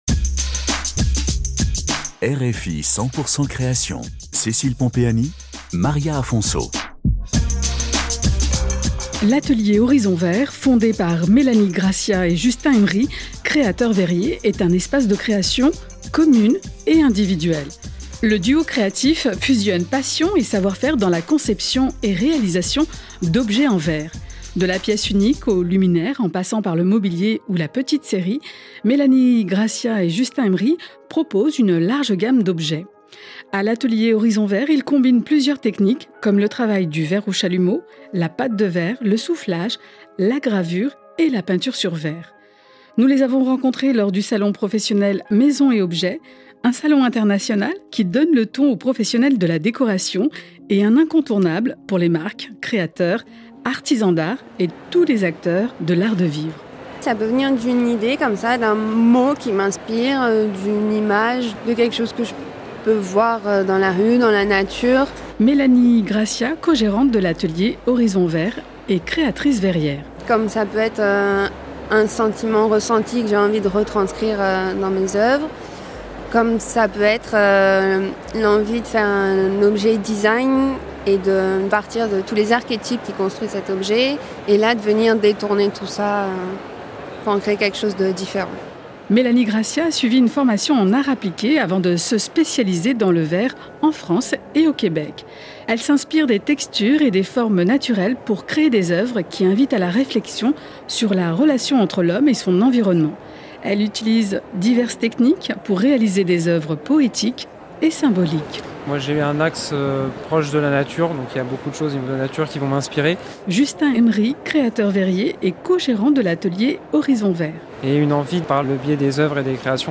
À l’atelier Horizon Verre, ils combinent plusieurs techniques, comme le travail du verre au chalumeau, la pâte de verre, le soufflage, la gravure et la peinture sur verre, pour créer des pièces uniques. Nous les avons rencontrés lors du Salon professionnel Maison & Objet.